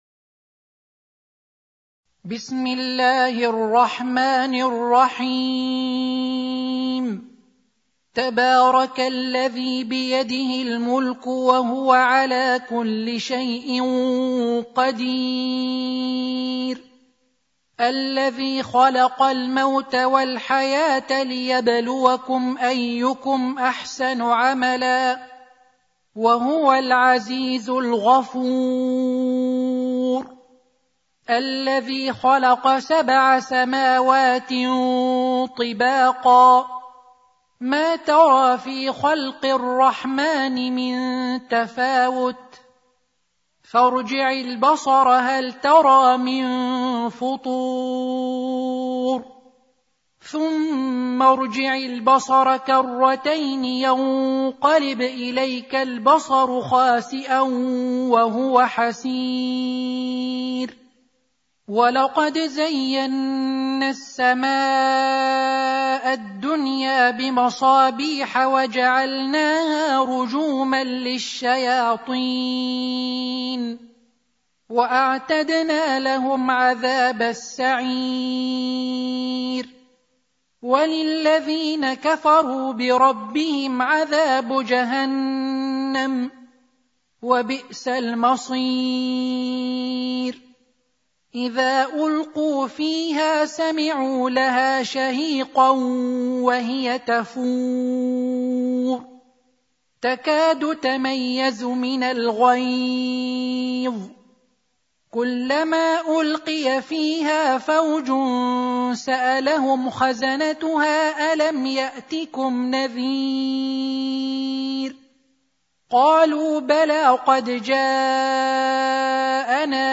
Surah Sequence تتابع السورة Download Surah حمّل السورة Reciting Murattalah Audio for 67. Surah Al-Mulk سورة الملك N.B *Surah Includes Al-Basmalah Reciters Sequents تتابع التلاوات Reciters Repeats تكرار التلاوات